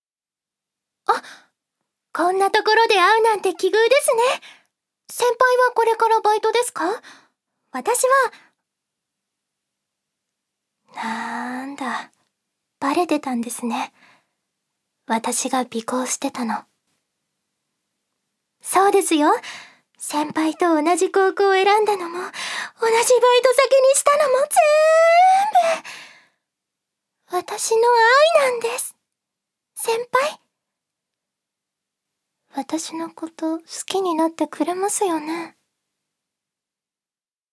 女性タレント
セリフ４